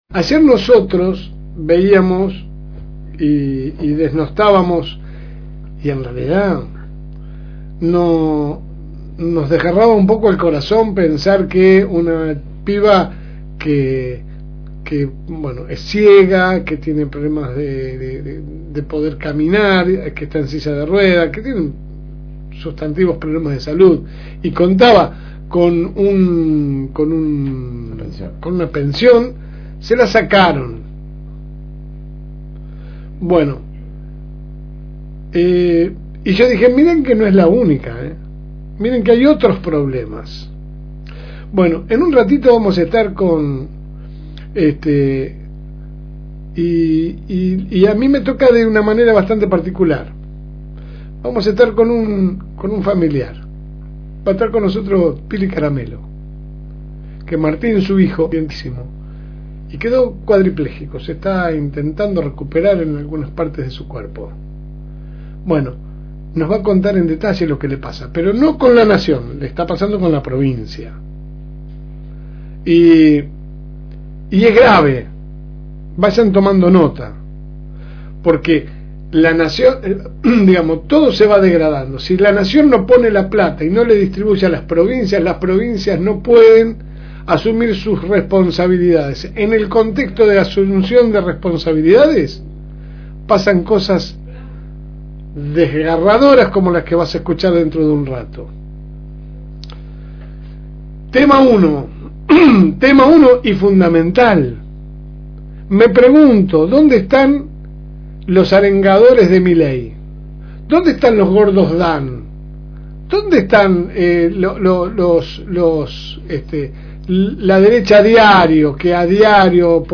Que sale de lunes a viernes por el aire de la Fm Reencuentro 102.9 de 10 a 12 HS